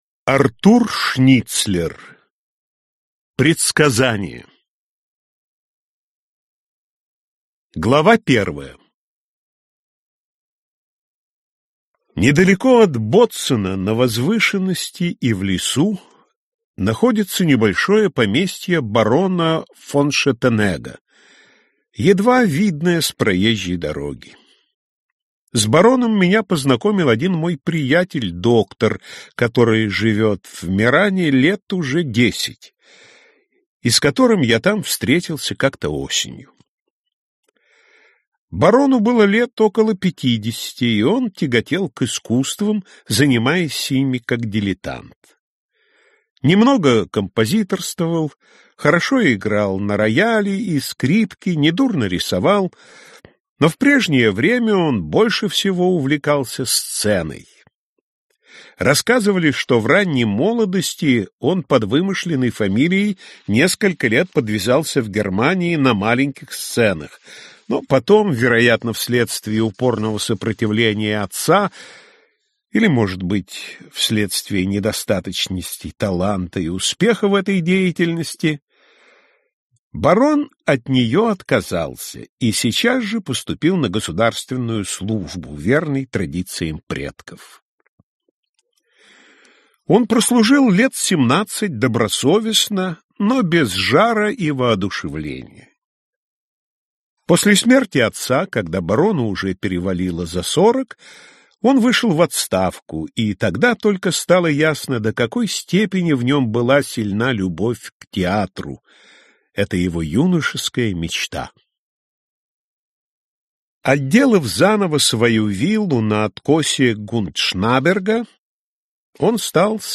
Aудиокнига Предсказание Автор Артур Шницлер Читает аудиокнигу Владимир Самойлов.